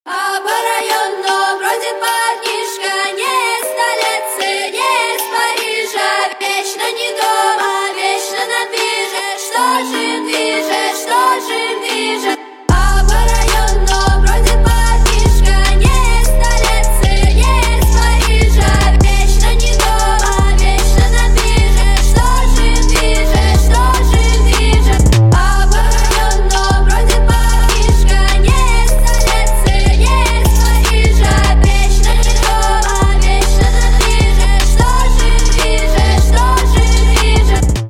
• Качество: 128, Stereo
русский рэп
мощные басы
Trap